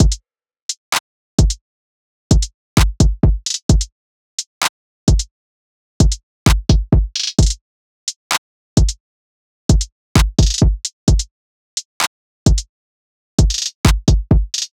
SOUTHSIDE_beat_loop_lime_full_02_130.wav